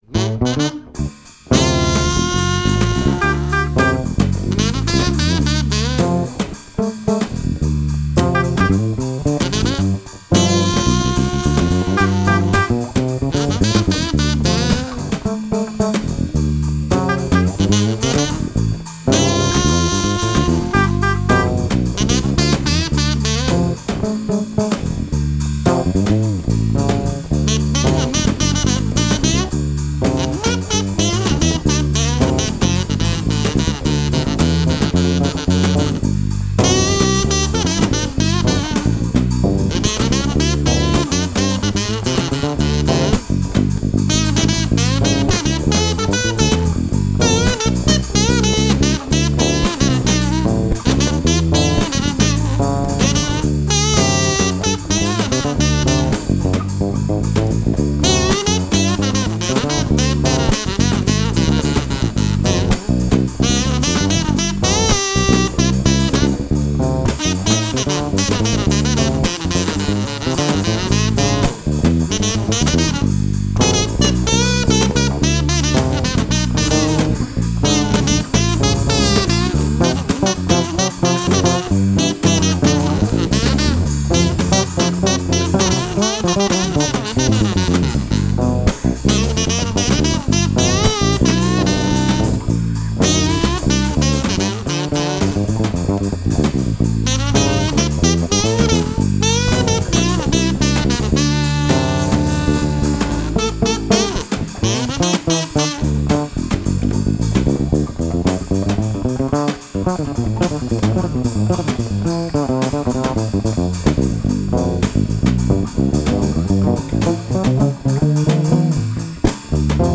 resonator bass
drums
drums/spoken word